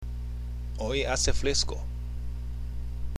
（オイ　アセ　フレスコ）